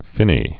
(fĭnē)